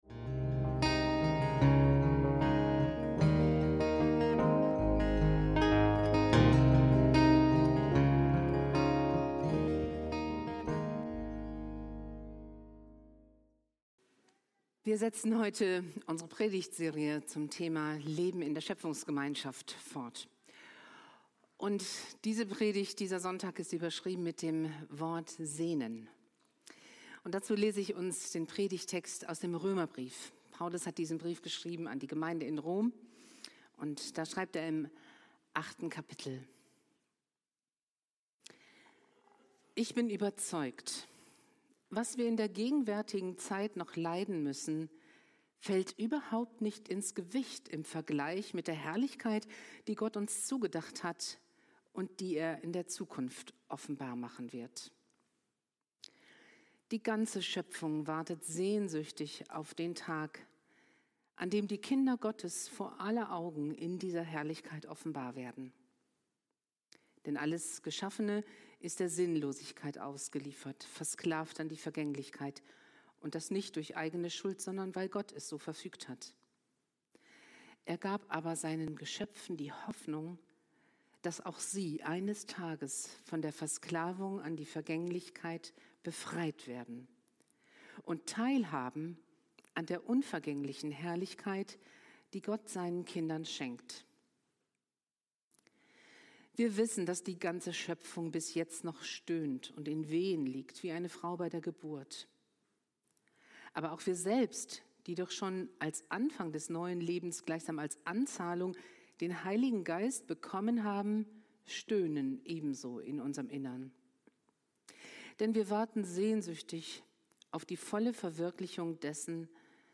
Leben in der Schöpfungsgemeinschaft – Sehnen: Wie die Zukunft der Schöpfung und der Kinder Gottes aussieht ~ FeG Bochum Predigt Podcast